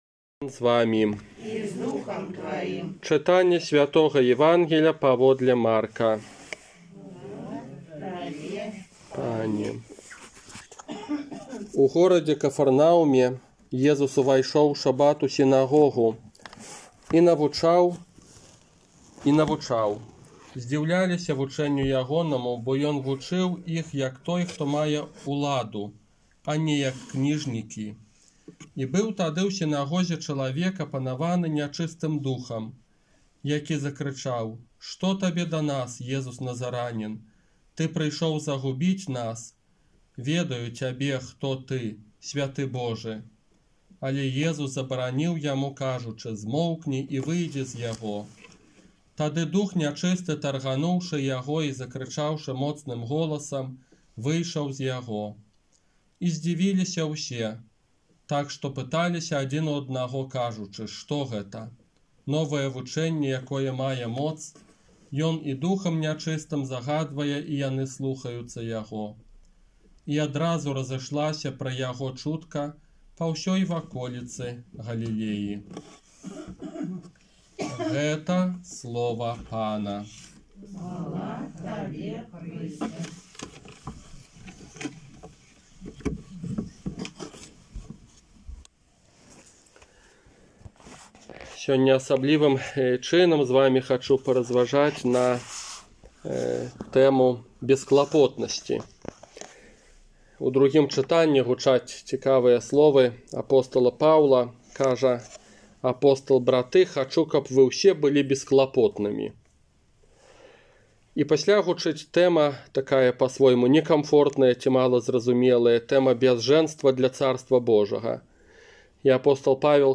ОРША - ПАРАФІЯ СВЯТОГА ЯЗЭПА
Казанне на чацвёртую звычайную нядзелю